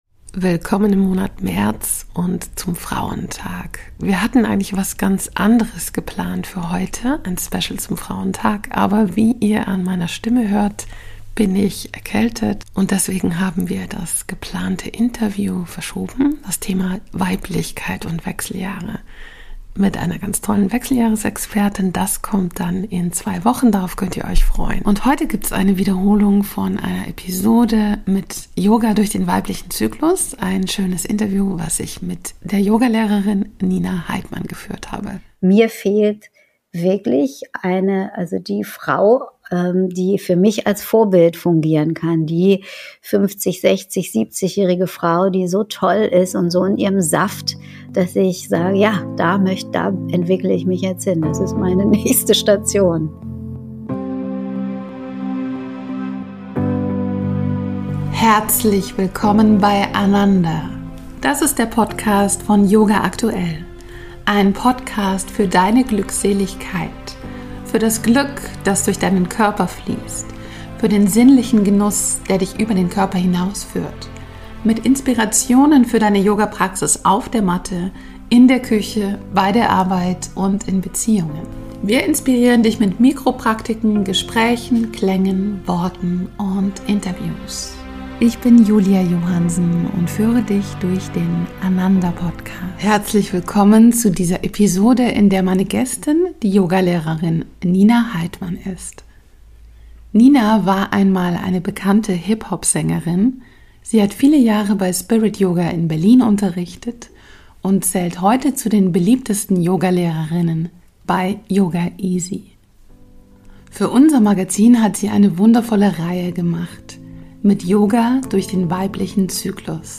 Statt einer Episode zum Thema Weiblichkeit & Wechseljahre (die kommt in 2 Wochen) eine Wiederholung des Interviews